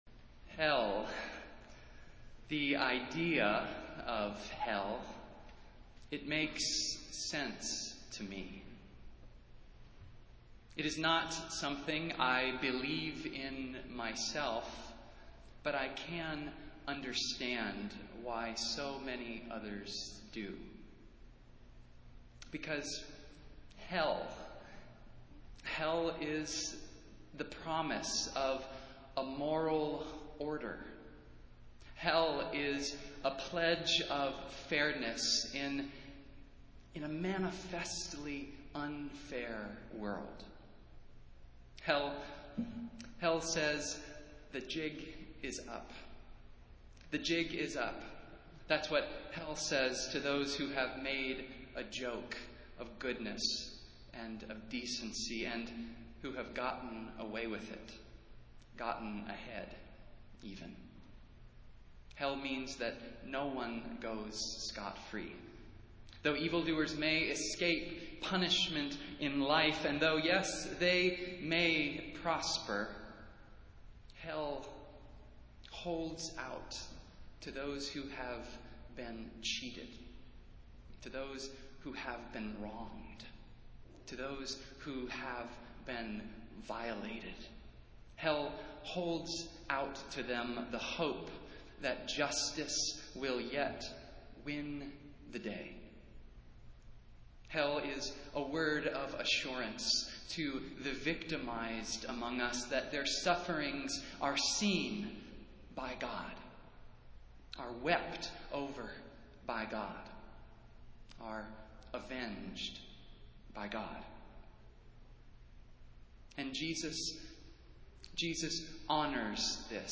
Festival Worship - Nineteenth Sunday after Pentecost